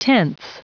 Prononciation du mot tenths en anglais (fichier audio)
Prononciation du mot : tenths